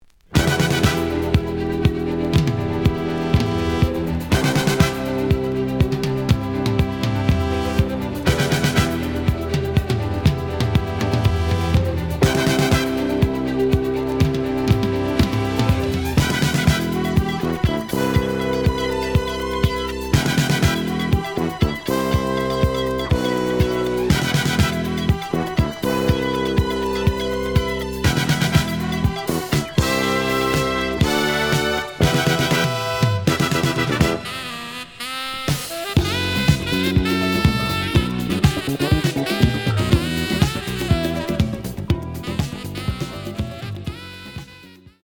(Mono)
試聴は実際のレコードから録音しています。
●Genre: Jazz Funk / Soul Jazz
●Record Grading: EX- (プロモ盤。)